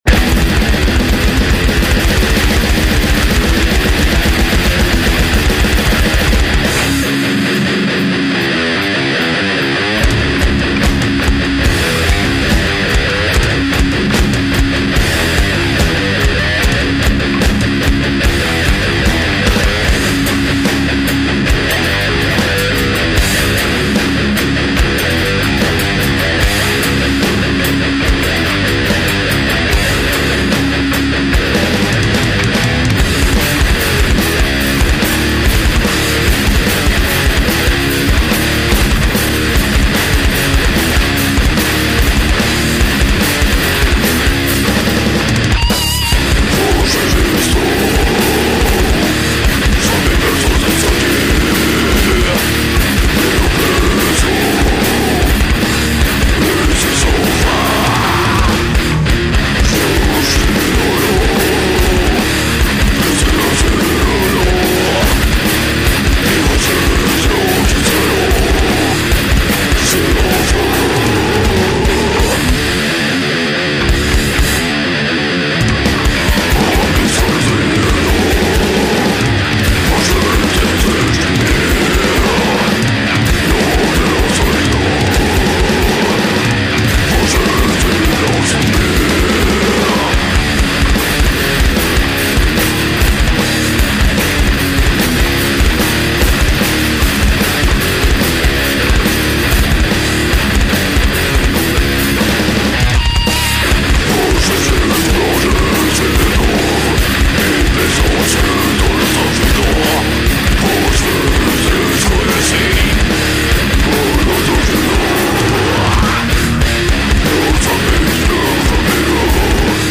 EstiloThrash Metal